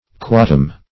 quotum - definition of quotum - synonyms, pronunciation, spelling from Free Dictionary